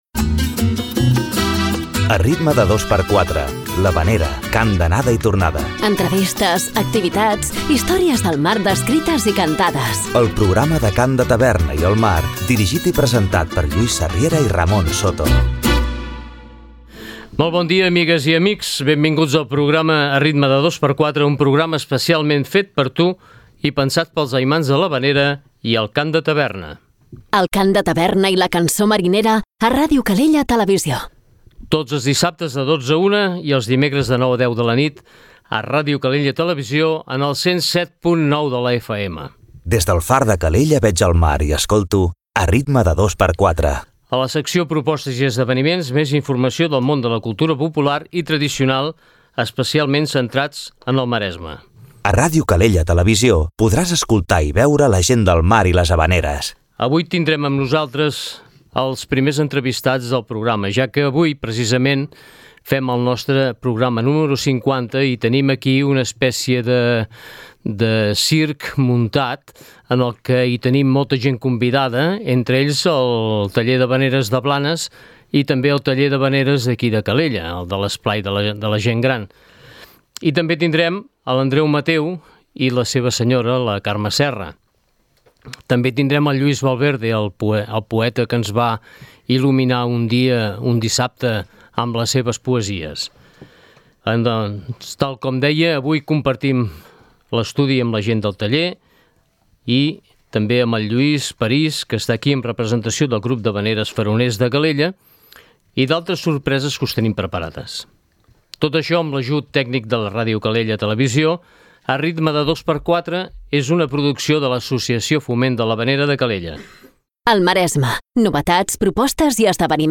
Aquesta setmana celebrem que ja portem 50 programes amb molta música i molts convidats!!